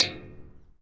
在一个生锈的大盘子上跳跃
描述：用Sony PCMD50录制。 在建筑工地上的一个生锈的大盘子上跳跃。
标签： 金属 生锈 击打 敲击 盘子
声道立体声